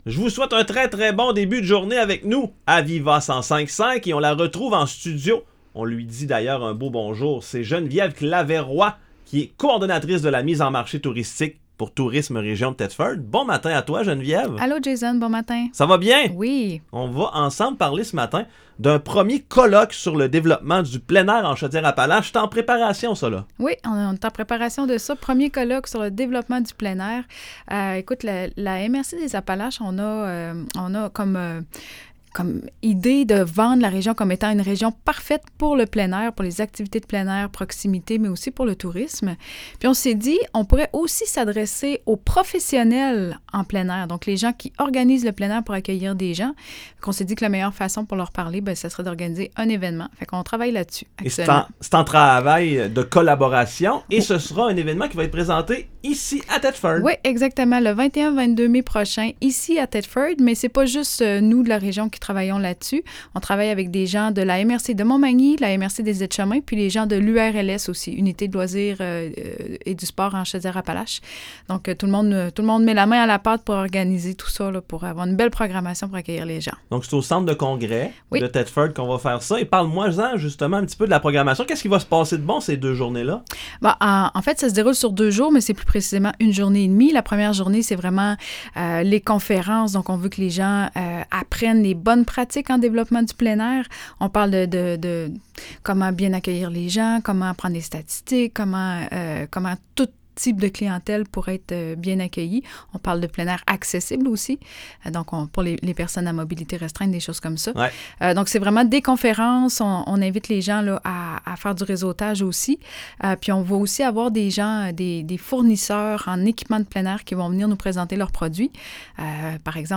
Chroniques radio
Les chroniques radio de la MRC des Appalaches sont diffusées à la station de radio VIVA 105,5, les mercredis chaque deux semaines, vers 8h00.